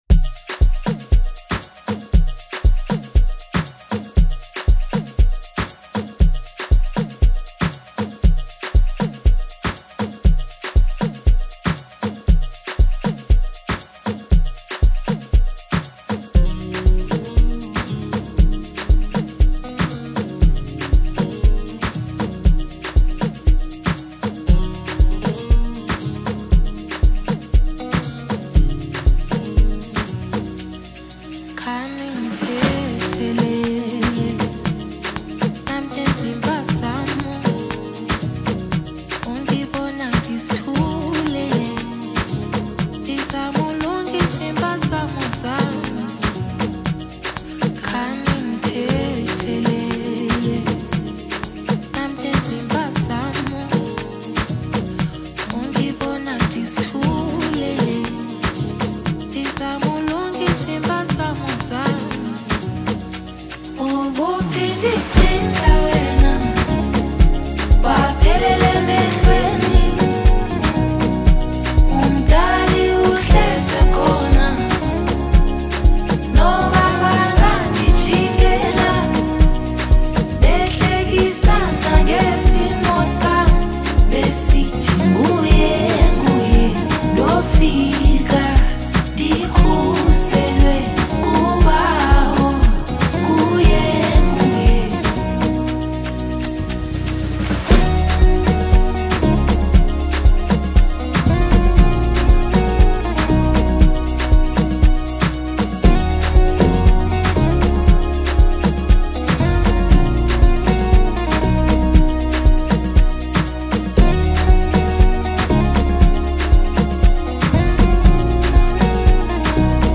another catchy record
South African female singer